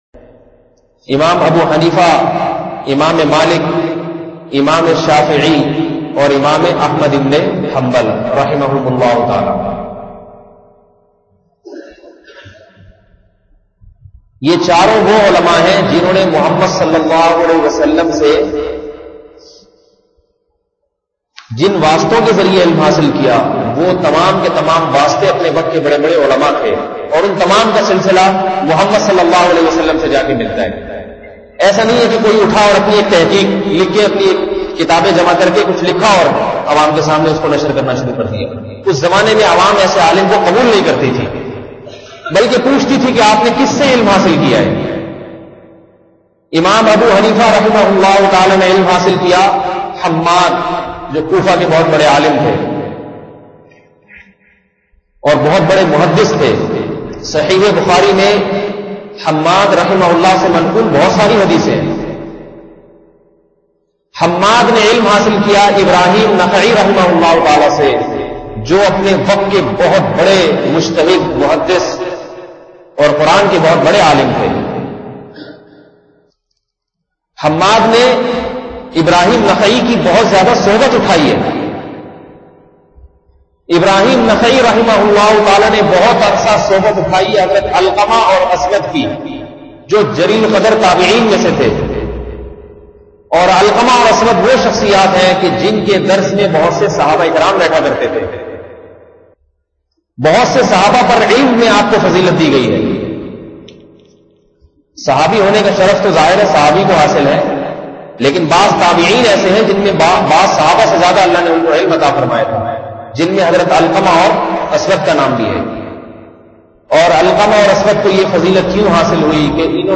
Audio Bayanat